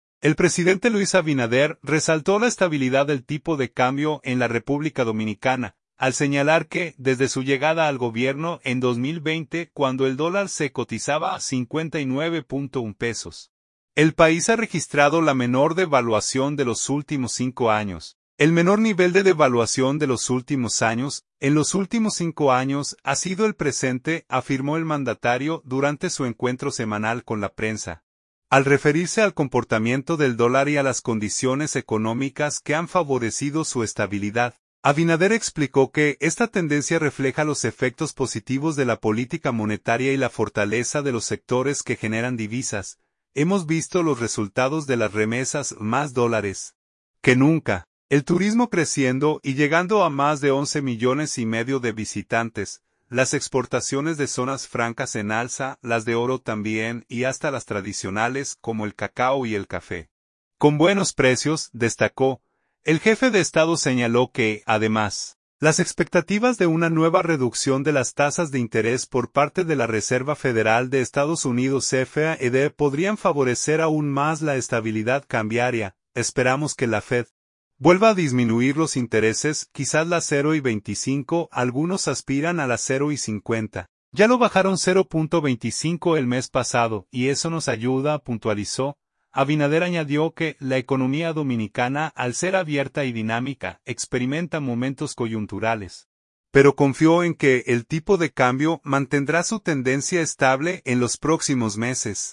“El menor nivel de devaluación de los últimos años, en los últimos cinco años, ha sido el presente”, afirmó el mandatario durante su encuentro semanal con la prensa, al referirse al comportamiento del dólar y a las condiciones económicas que han favorecido su estabilidad.